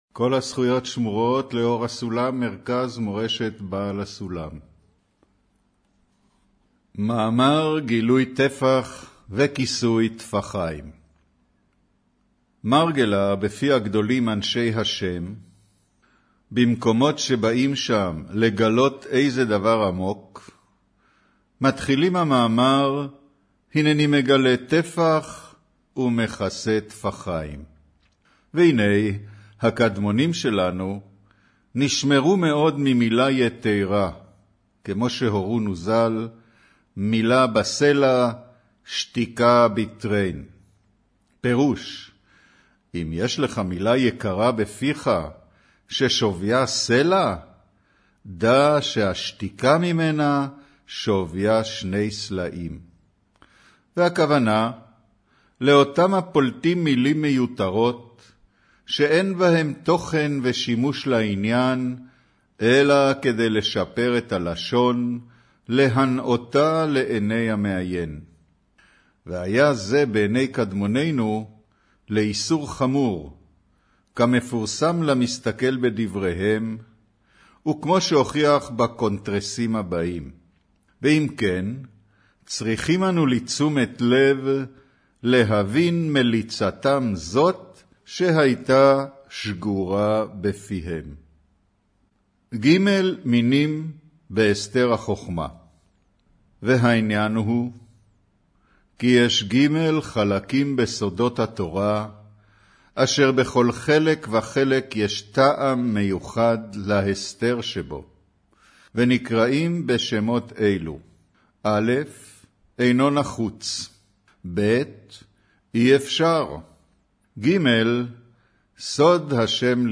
אודיו - קריינות